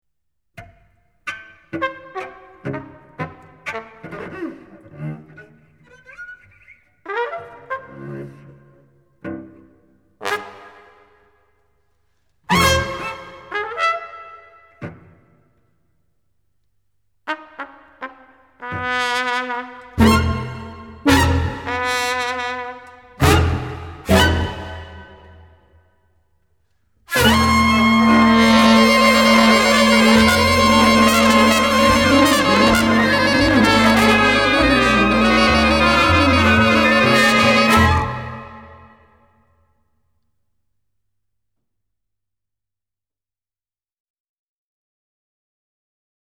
Improvised music by its members